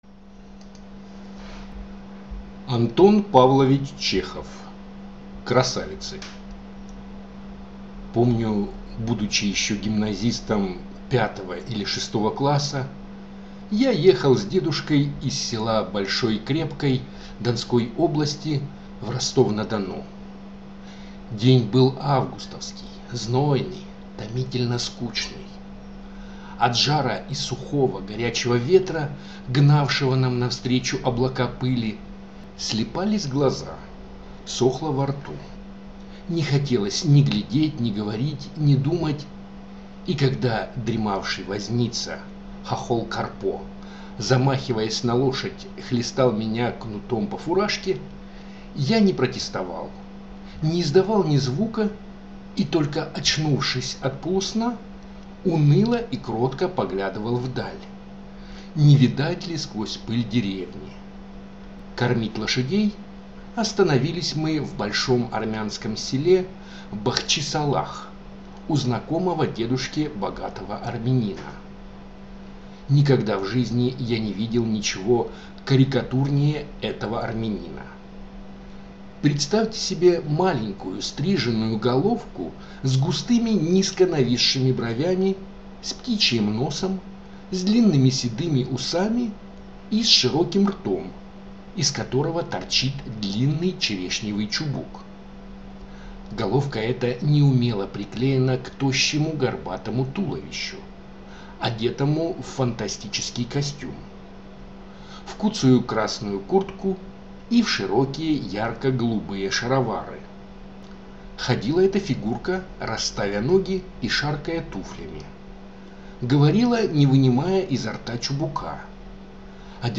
Аудиокнига Красавицы | Библиотека аудиокниг